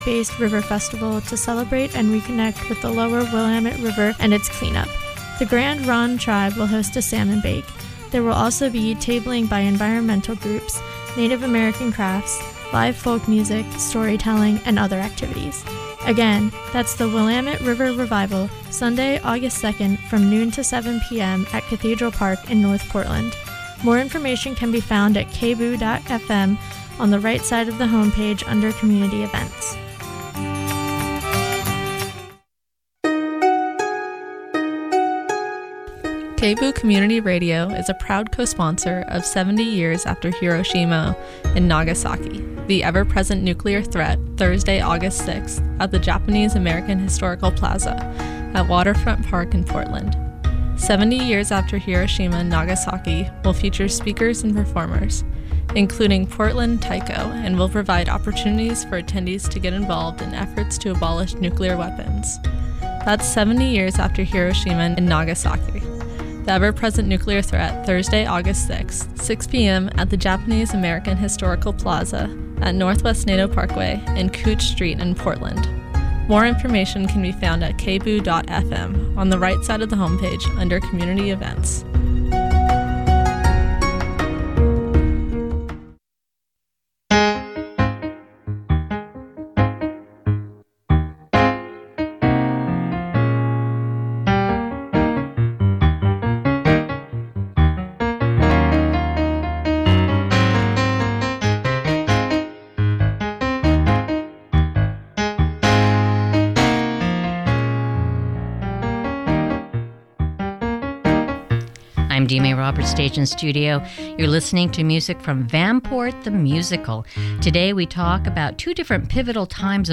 Her interview was recorded live on location on 4/23/15.